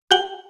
Buzz Error (6).wav